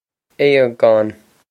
Aogán Ay-gawn
Pronunciation for how to say
This is an approximate phonetic pronunciation of the phrase.